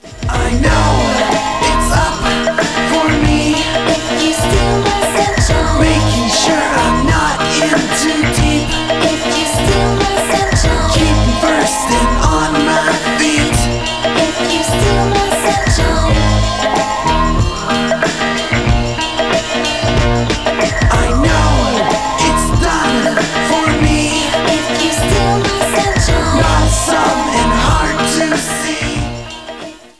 hiphop group